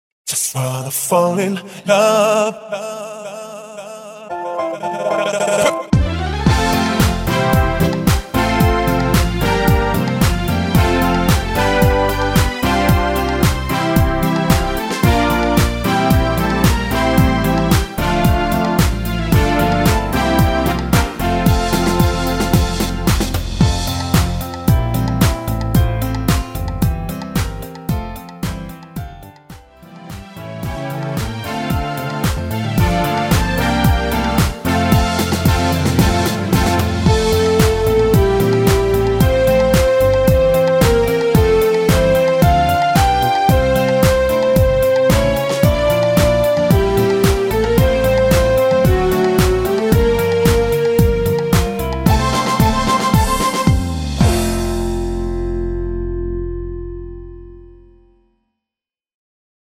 엔딩이 길어서 라이브 하시기좋도록 짧게편곡 하였습니다.(미리듣기 참조)
◈ 곡명 옆 (-1)은 반음 내림, (+1)은 반음 올림 입니다.
앞부분30초, 뒷부분30초씩 편집해서 올려 드리고 있습니다.